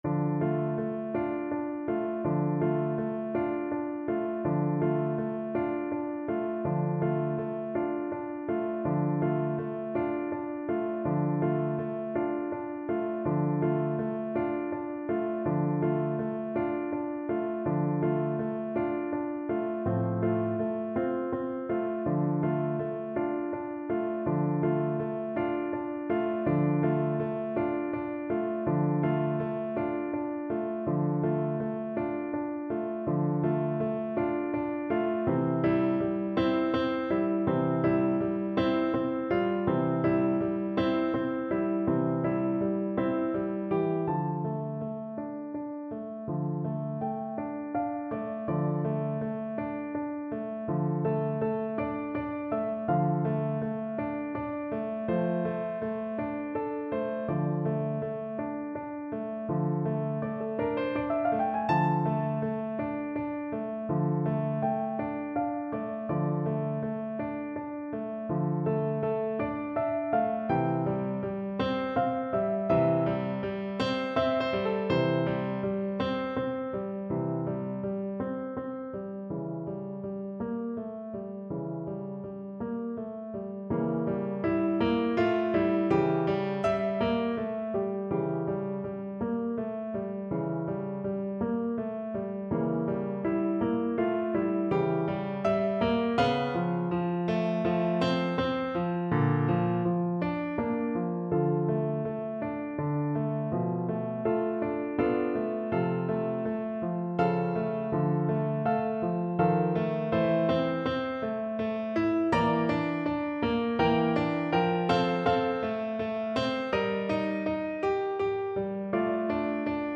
Free Sheet music for Cello
Cello
D minor (Sounding Pitch) (View more D minor Music for Cello )
=172 Lento = 112
6/8 (View more 6/8 Music)
Classical (View more Classical Cello Music)